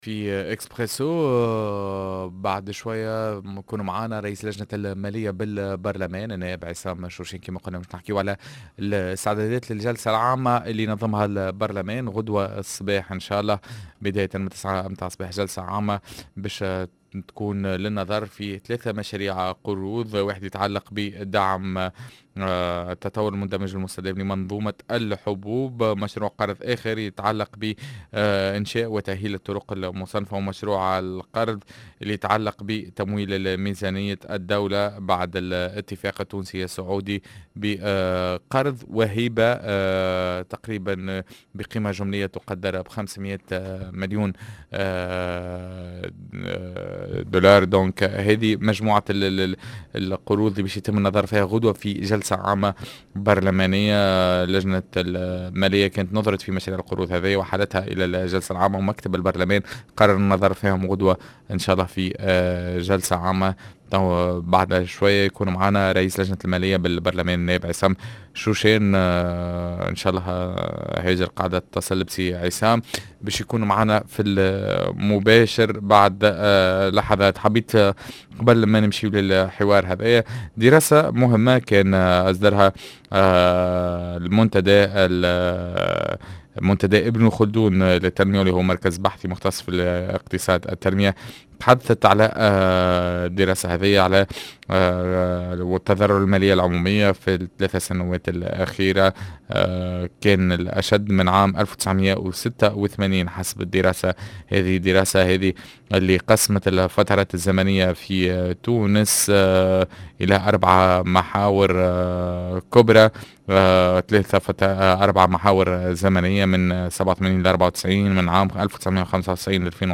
عصام شوشان- نائب بالبرلمان ورئيس لجنة المالية للحديث حول القروض المبرمجة